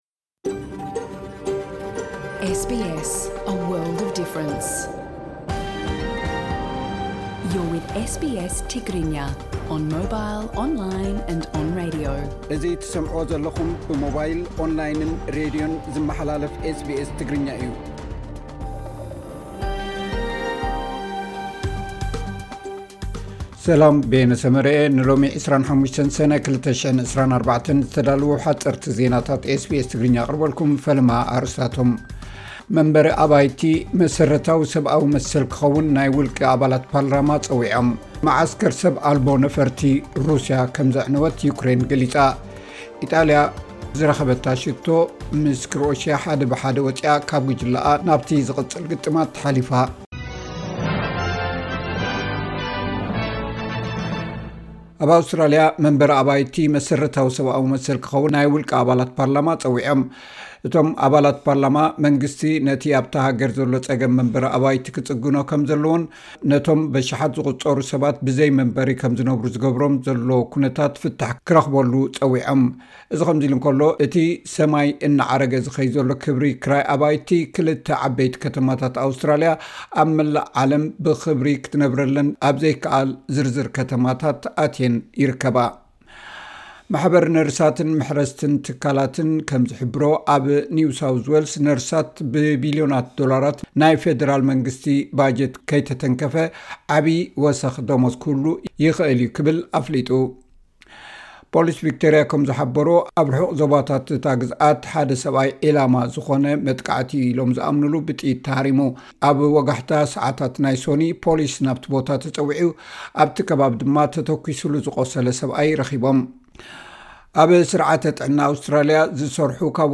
ሓጸርቲ ዜናታት ኤስ ቢ ኤስ ትግርኛ (25 ሰነ 2024)